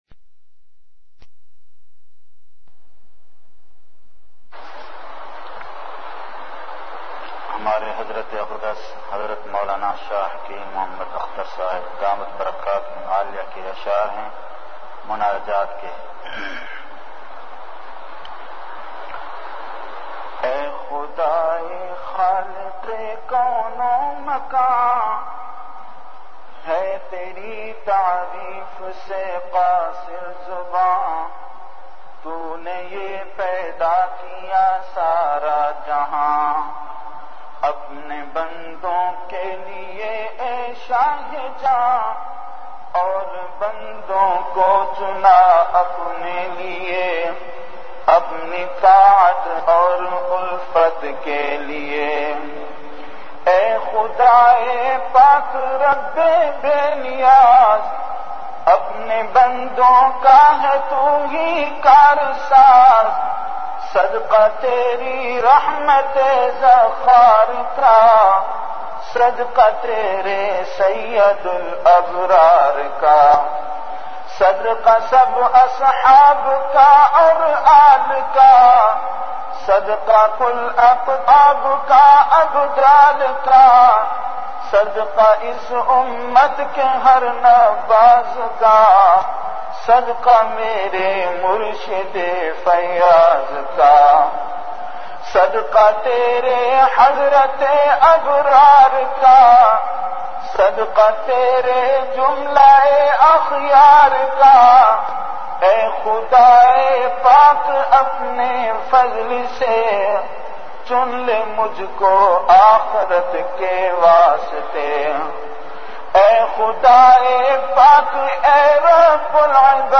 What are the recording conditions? Delivered at Home. Download MP3 Share on WhatsApp Audio Details Category Ashaar Duration 82.5 min Date 05-May-2005 Islamic Date Venue Home Event / Time After Isha Prayer Listeners 1,277 File Size 12.2 MB Have a question or thought about this bayan?